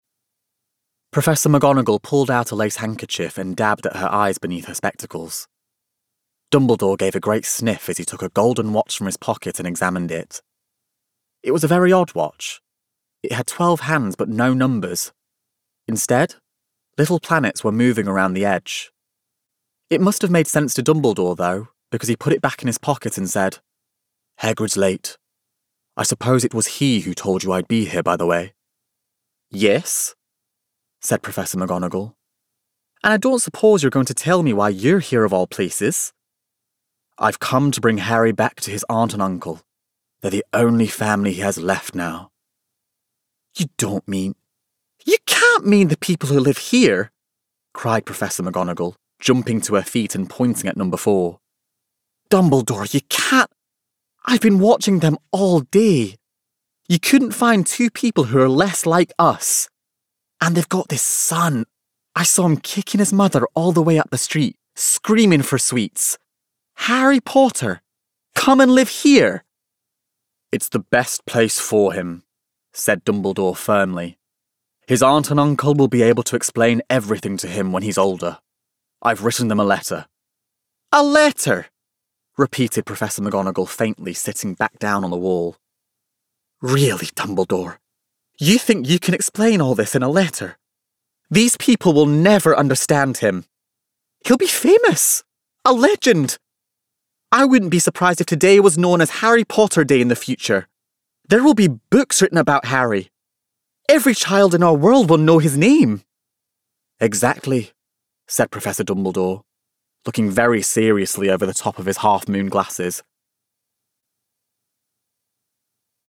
Voice Reel
Audiobook